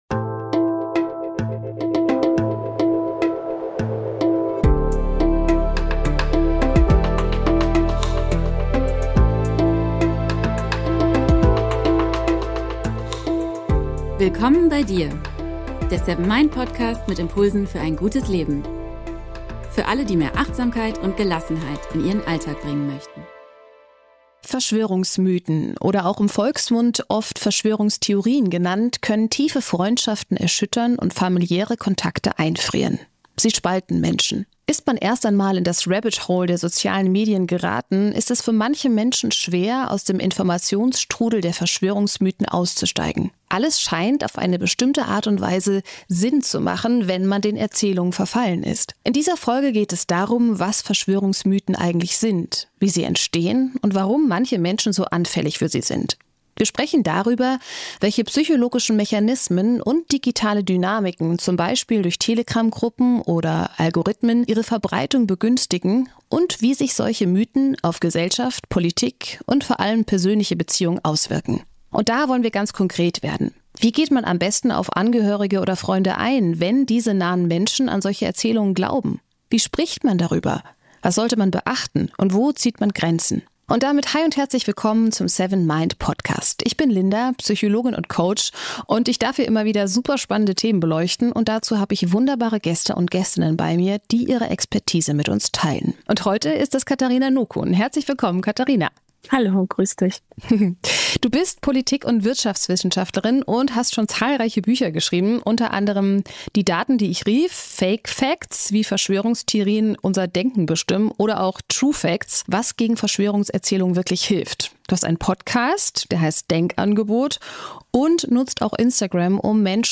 Verschwörungserzählungen & warum sie Menschen so stark beeinflussen - Interview